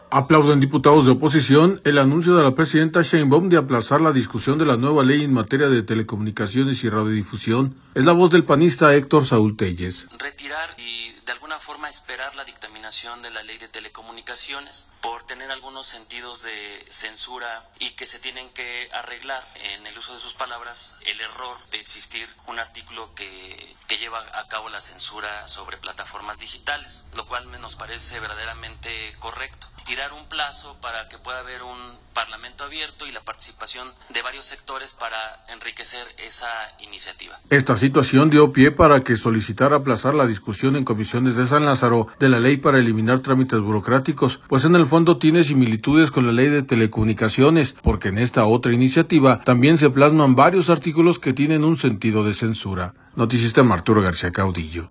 Aplauden diputados de oposición anuncio de la presidenta Sheinbaum de aplazar discusión de la nueva Ley en materia de Telecomunicaciones y Radio Difusión. Es la voz del panista Héctor Saúl Téllez.